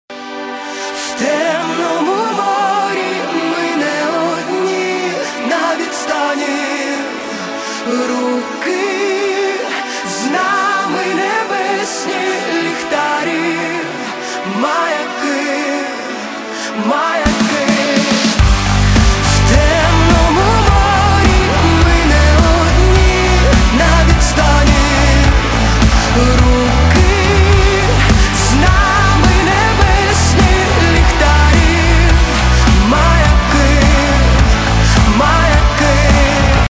• Качество: 320, Stereo
громкие
Драйвовые
женский вокал
Alternative Rock
украинский рок
сильный голос